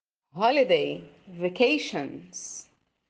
Attention to pronunciation 🗣